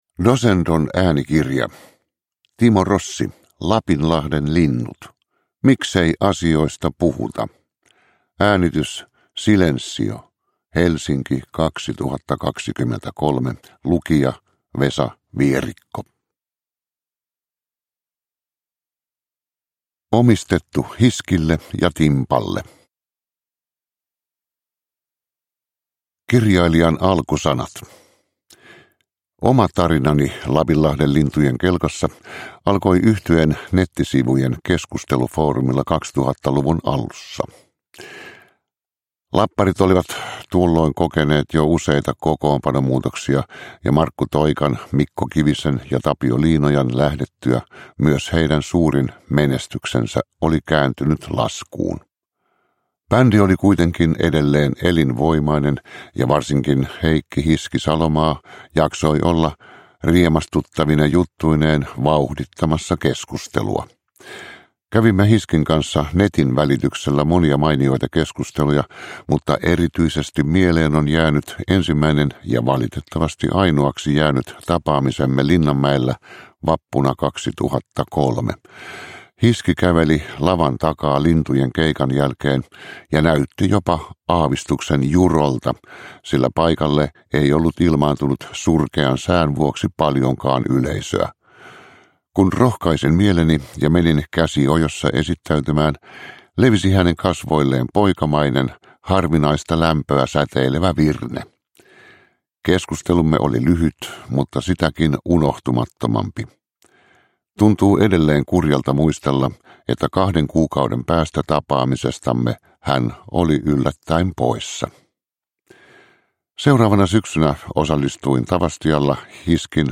Uppläsare: Vesa Vierikko